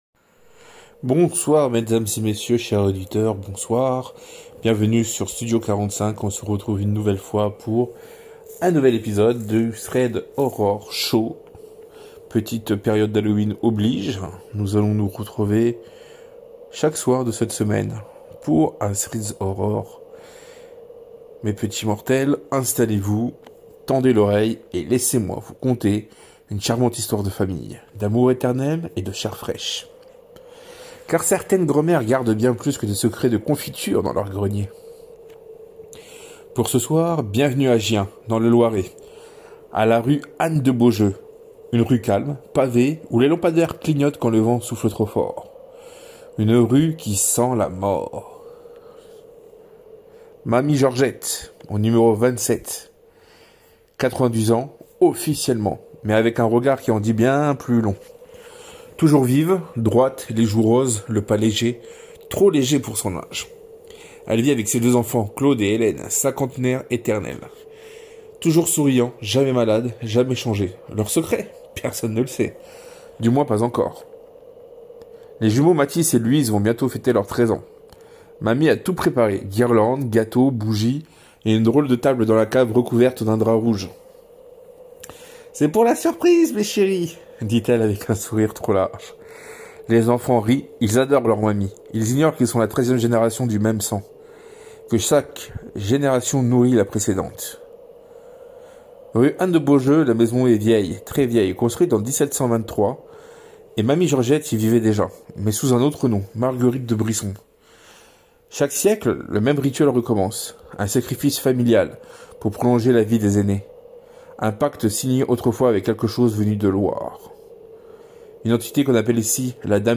Avec une ambiance sonore immersive et une tension qui monte jusqu’à l’insoutenable, Threads of Horror signe un nouvel épisode glaçant, où les fantômes ne se cachent pas toujours sous les lits — parfois, ils nous appellent par notre prénom.